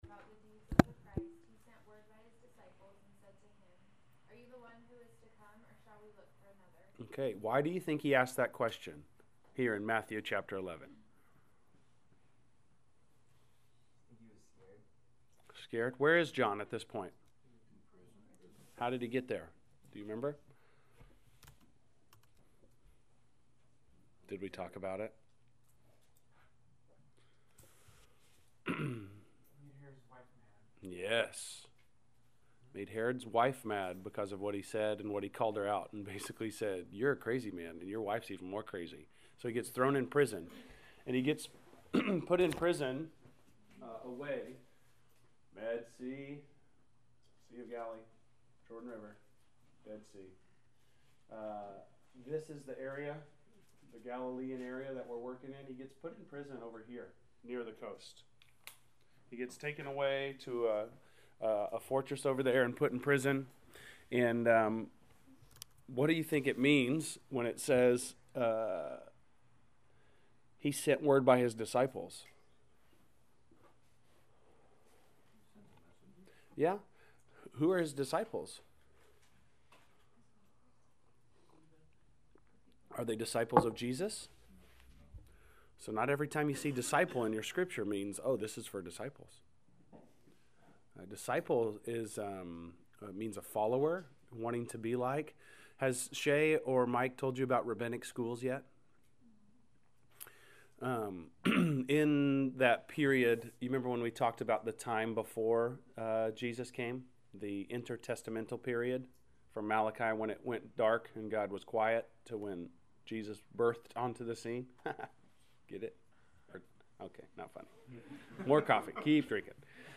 Class Session Audio October 23